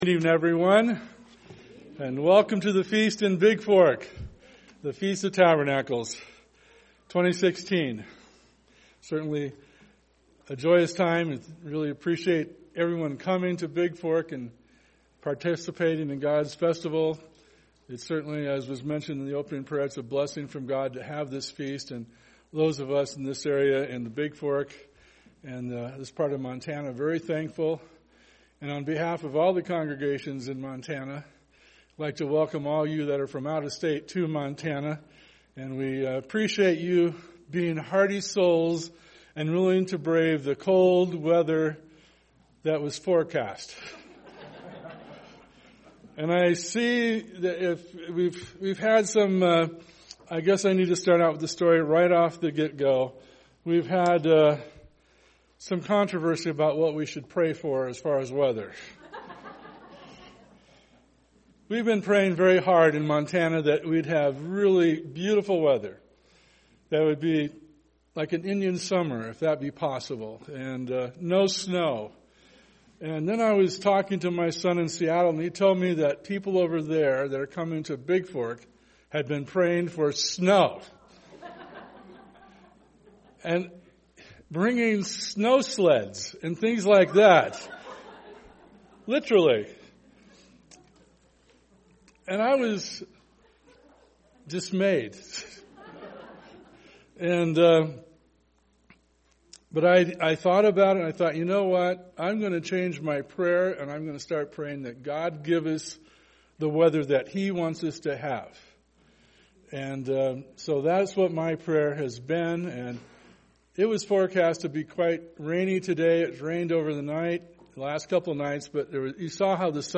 This sermon was given at the Bigfork, Montana 2016 Feast site.